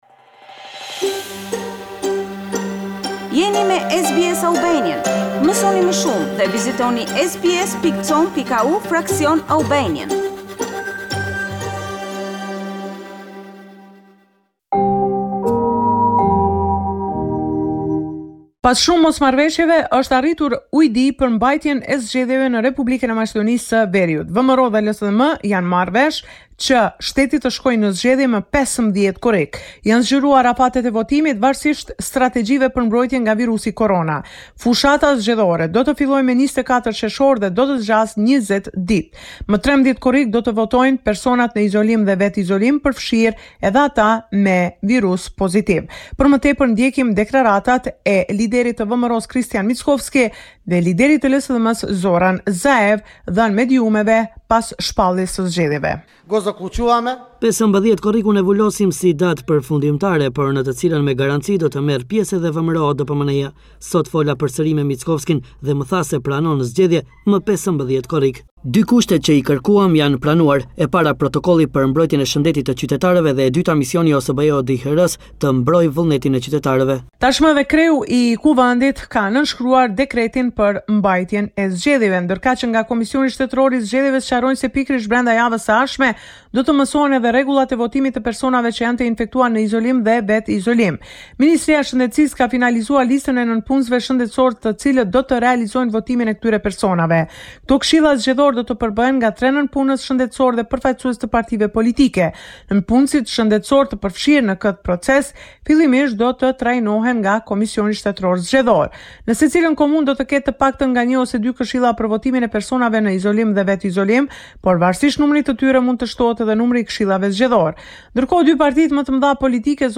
This is a report summarising the latest developments in news and current affairs in North Macedonia.